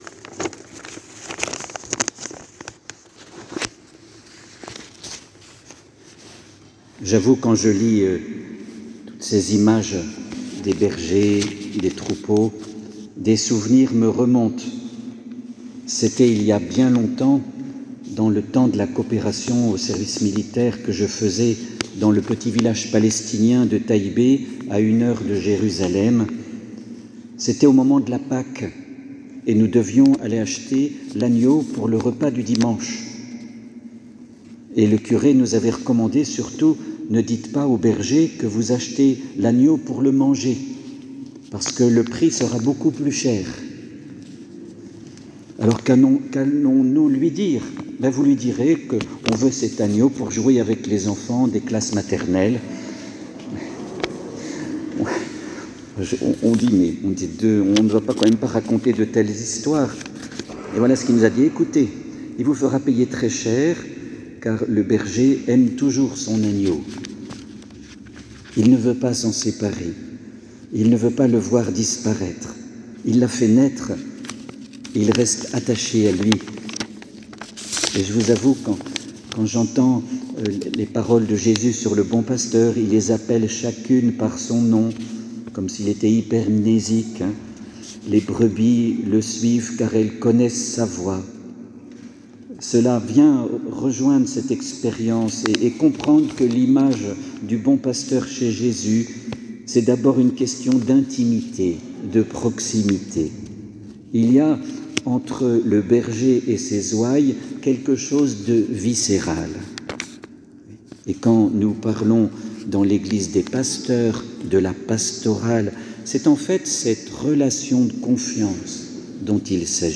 Homelie-les-trois-images-du-bon-berger.mp3